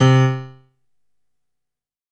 55ay-pno02-c2.wav